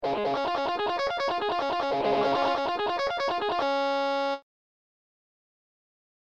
Greg howe > Tapping Arpeggio Lick
Tapping+Arpeggio+Lick.mp3